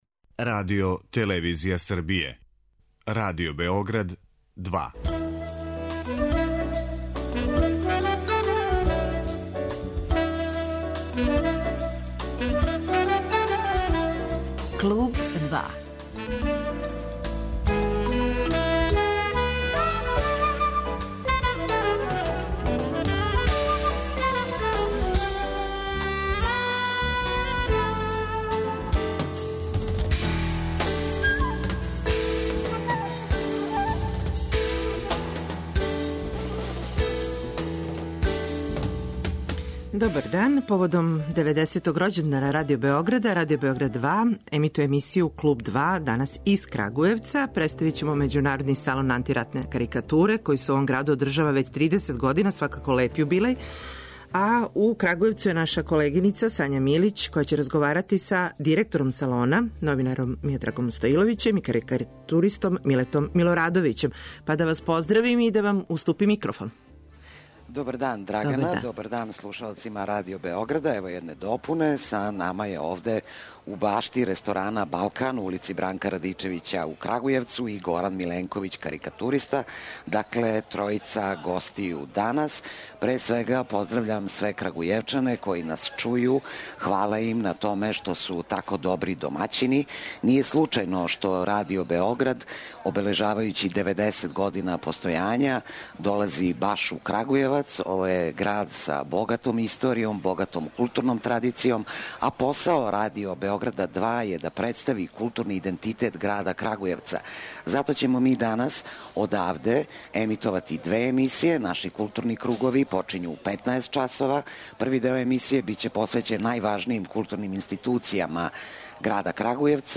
Поводом деведесетог рођендана Радио Београда емисију радимо у Крагујевцу.